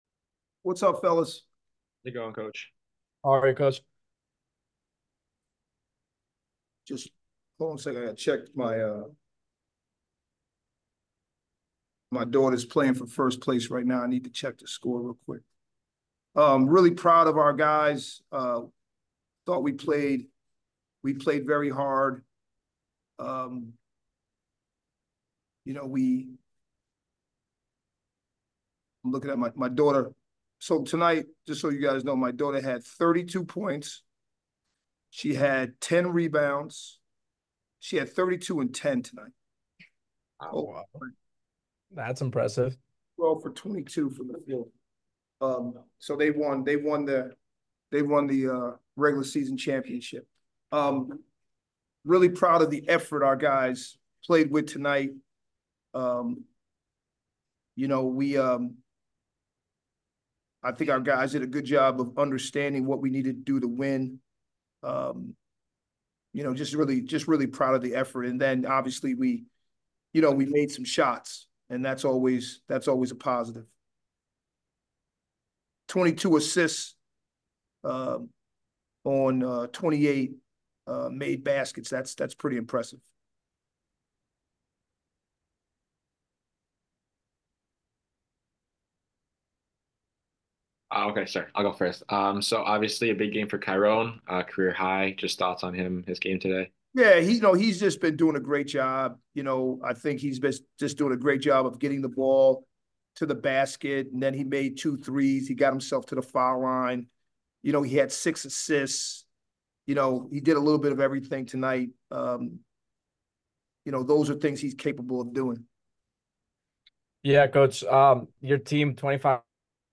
Men's Basketball / Bucknell Postgame Interview